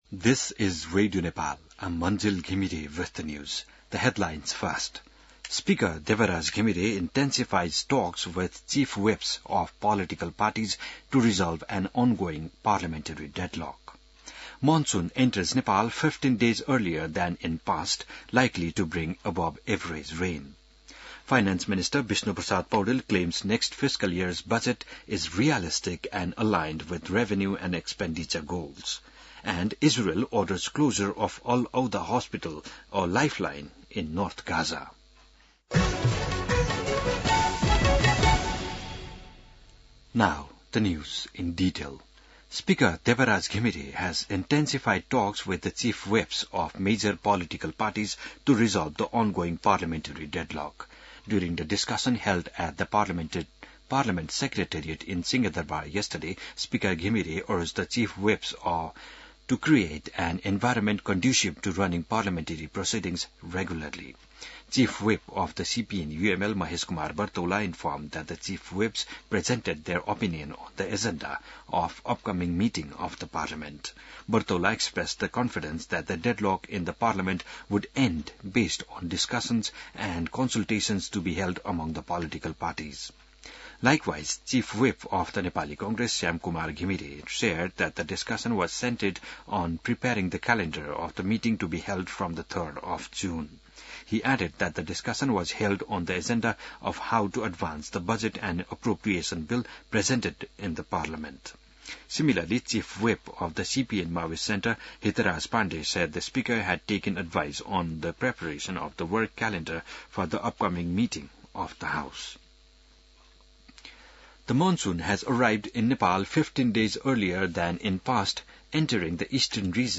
बिहान ८ बजेको अङ्ग्रेजी समाचार : १७ जेठ , २०८२